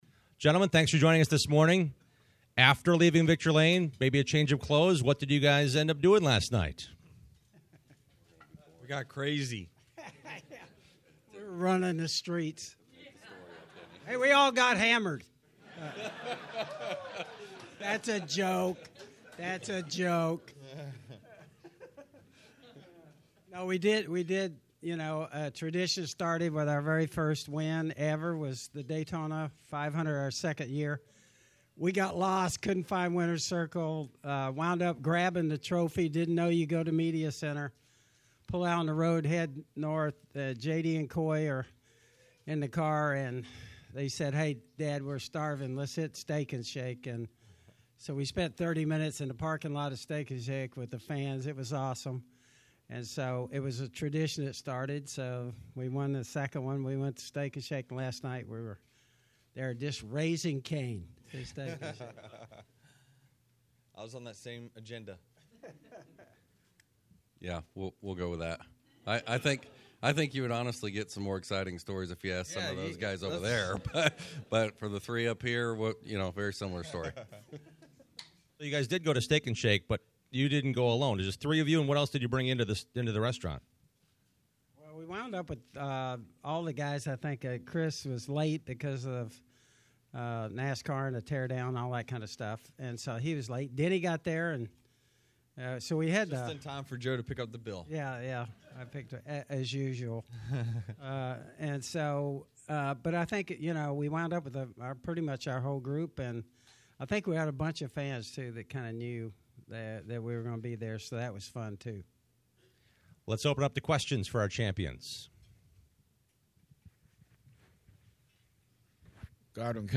Champion’s breakfast interview: